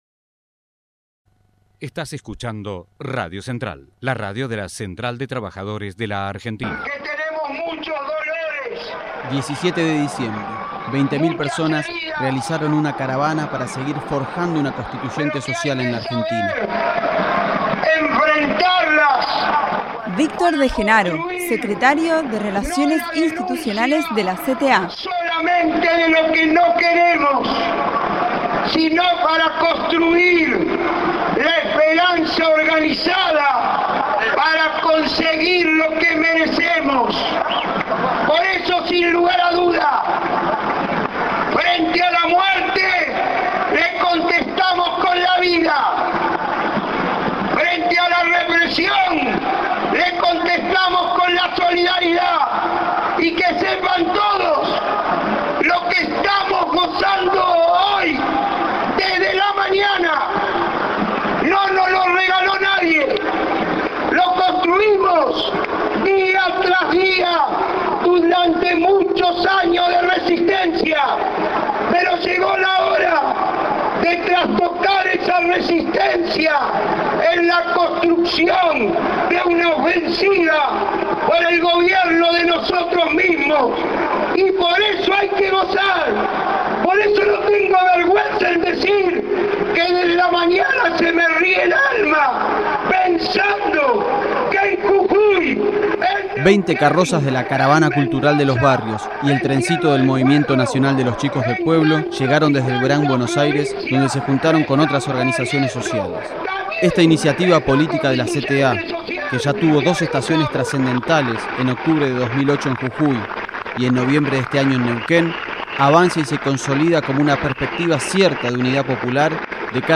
Masivo acto frente al Congreso Nacional
17-12_VICTOR_MARCHA.mp3